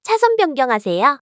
audio_lanechange.wav